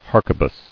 [har·que·bus]